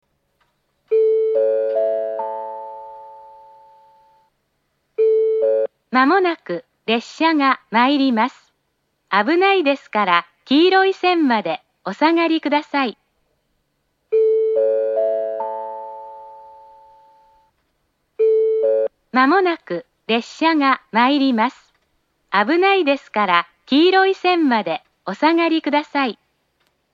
３番線接近放送 このホームで折り返し運転を行っていました。